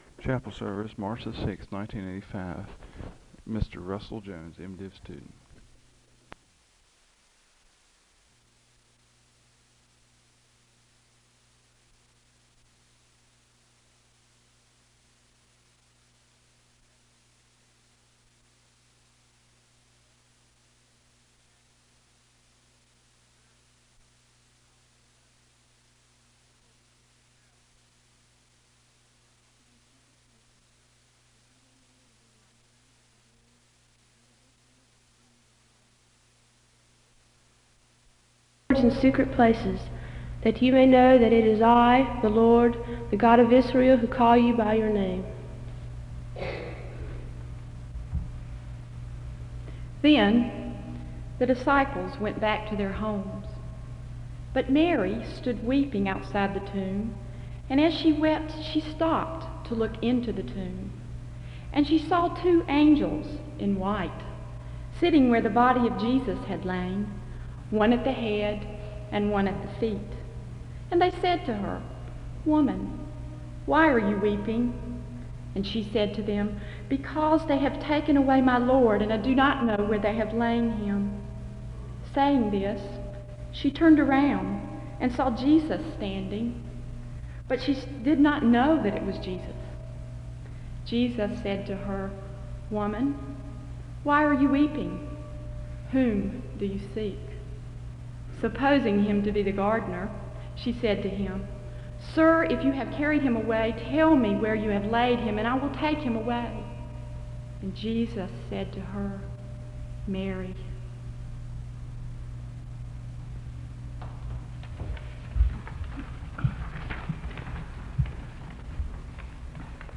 Disclaimer: The first portion of the service is inaudible.
The service begins with Scripture readings (0:00-1:59). Several women give a presentation about the birth of Jesus (2:00-5:03).
The congregation shares in reciting the litany of affirmation (18:30-20:59). The service ends with a benediction (21:00-21:33).